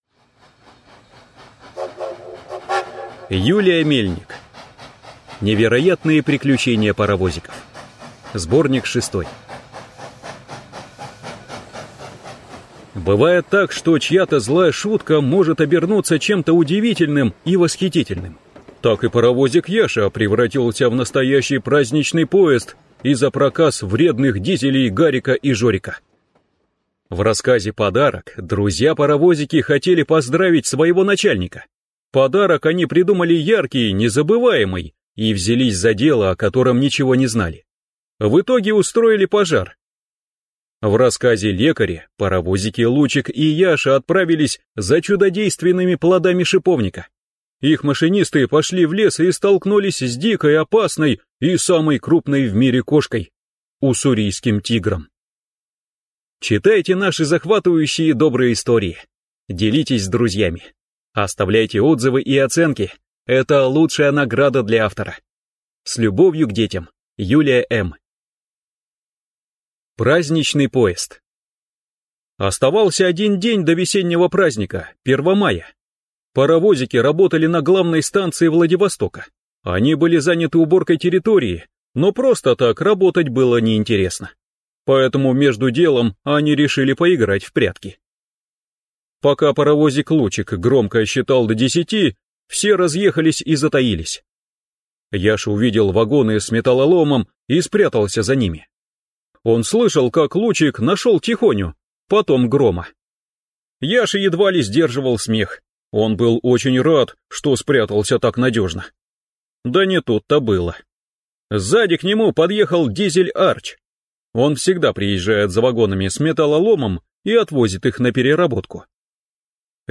Аудиокнига Невероятные приключения паровозиков. Сборник 6 | Библиотека аудиокниг